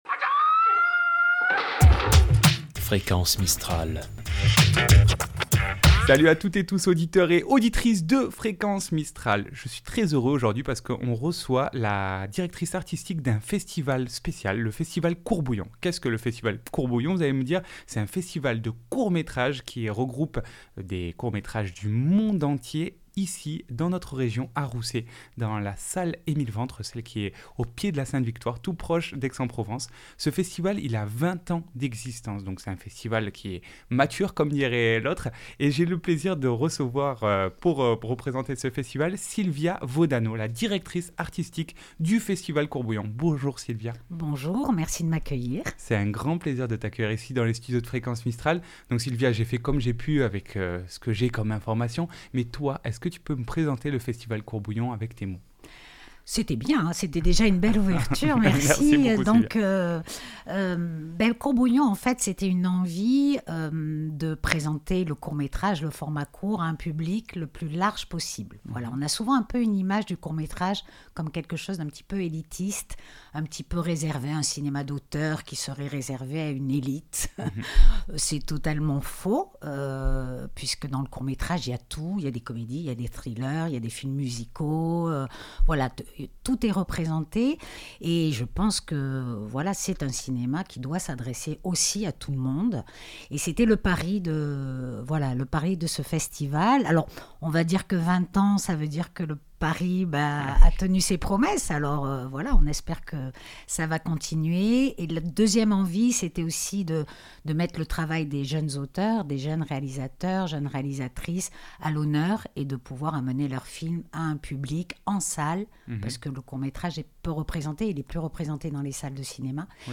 ITW festival Court Bouillon .mp3 (20.04 Mo)